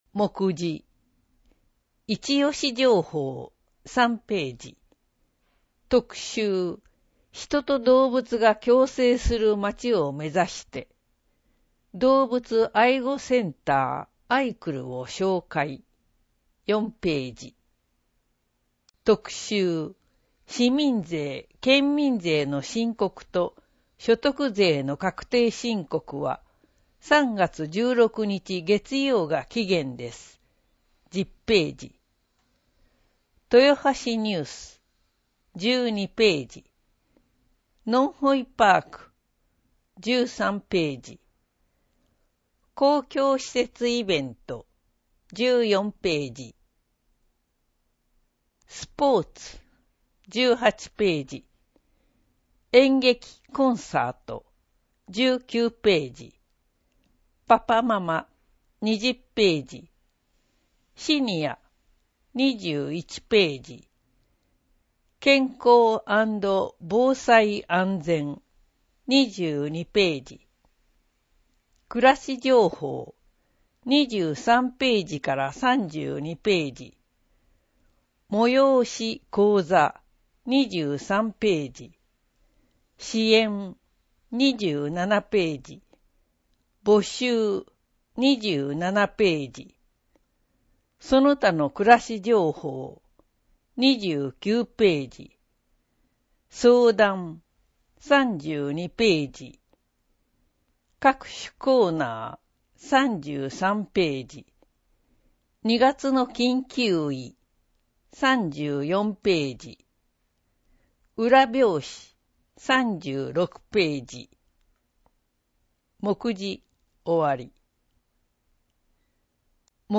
• 「広報とよはし」から一部の記事を音声でご案内しています。